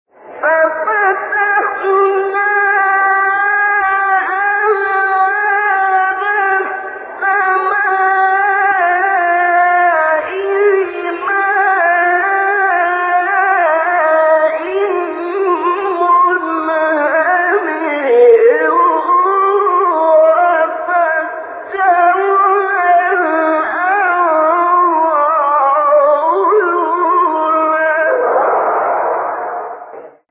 سوره : قمر آیه: 11-12 استاد : ابوالعینین شعیشع مقام : چهارگاه قبلی بعدی